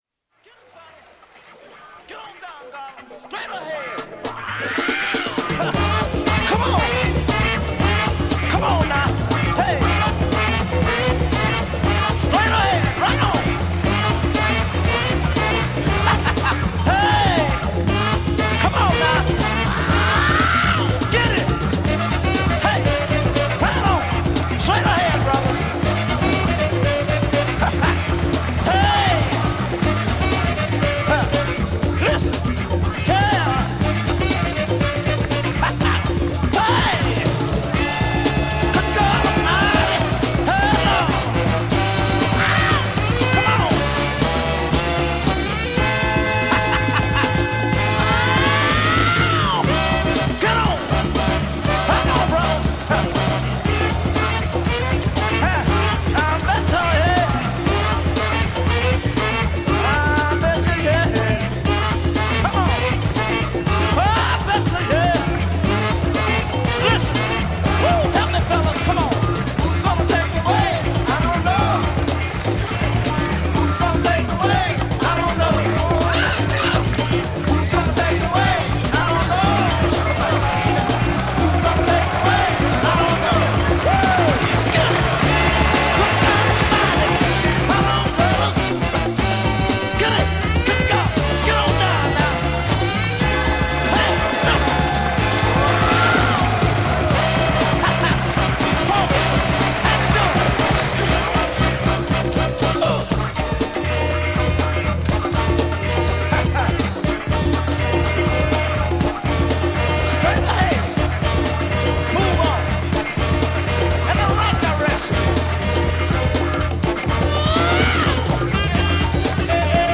French funk
Rare French Afro funk single 2Siders!!